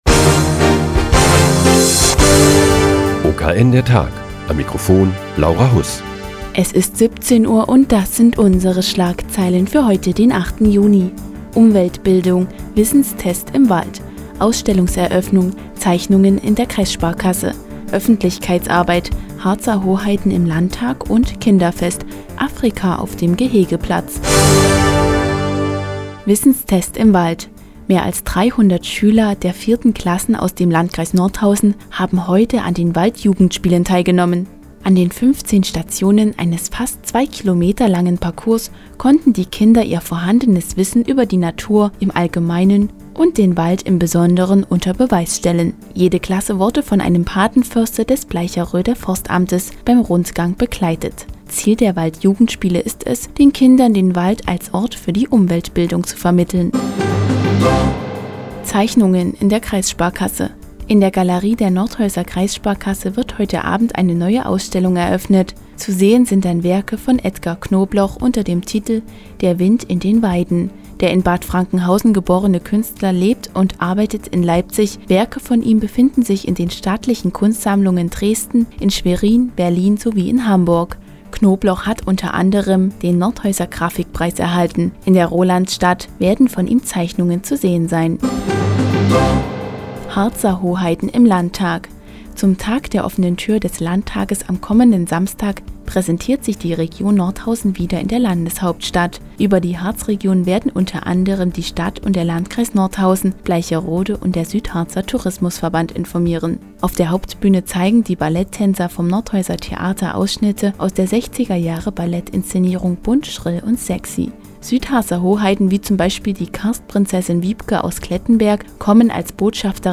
Die tägliche Nachrichtensendung des OKN ist nun auch in der nnz zu hören. Heute geht es um die Waldjugendspiele, die Region Nordhausen bei dem Tag der offenen Tür des Landtages und das Kinderfest auf dem Rolandsfest.